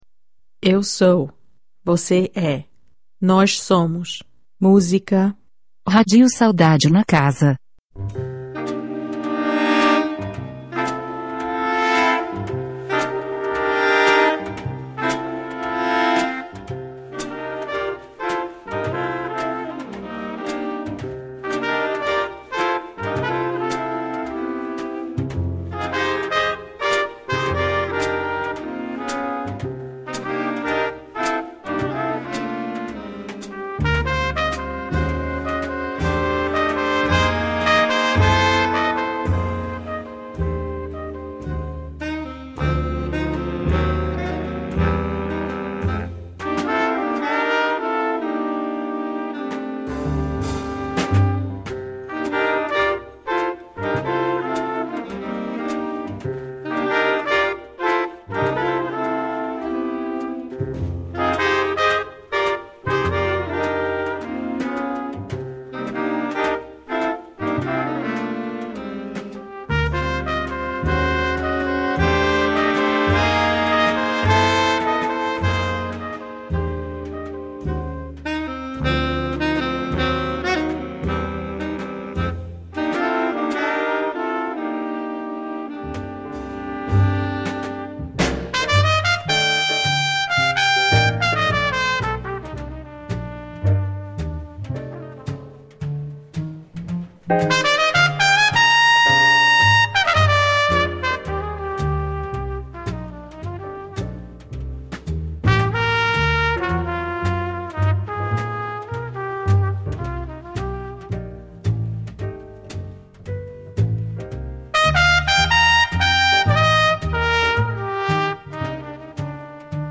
Tonight it’s strictly jazz selections to illumine the scene.